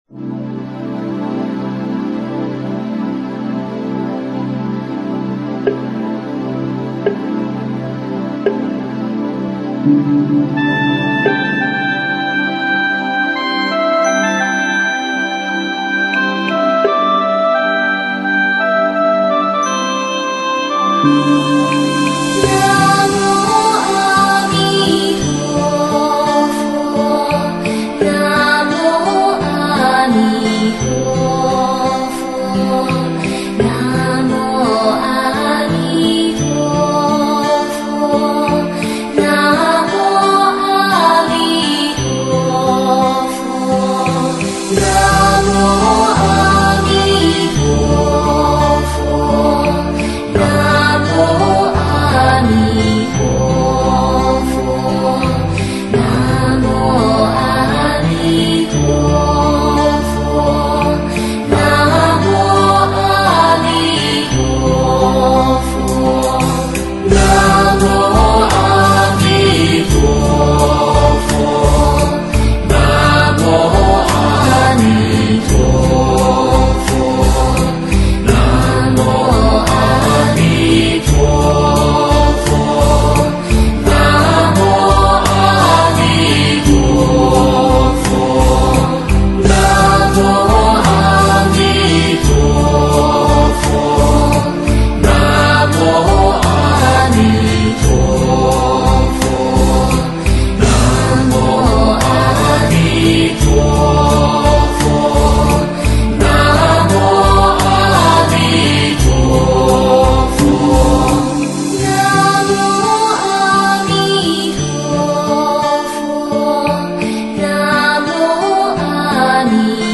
四字四音念佛，适合日常念佛、经行绕佛。清净庄严的阿弥陀佛圣号念诵。